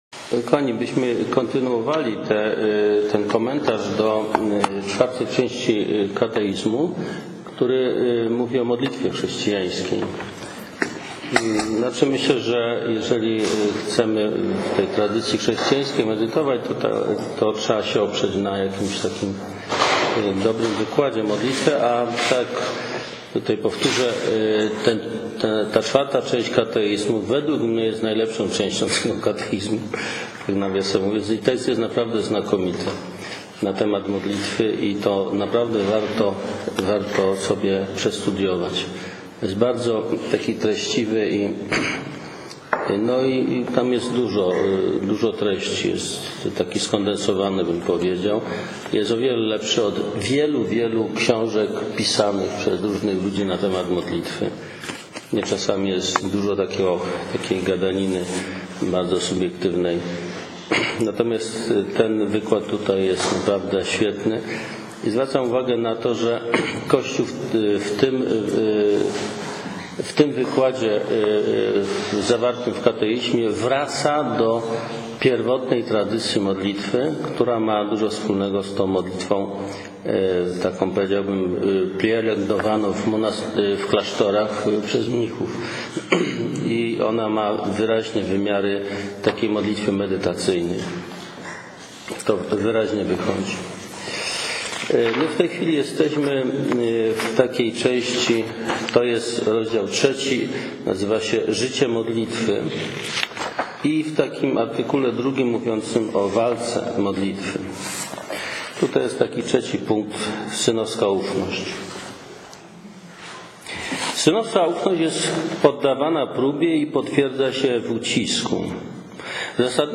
Nagrania audio konferencji: Modlitwa w życiu chrześcijańskim (wg Katechizmu Kościoła Katolickiego)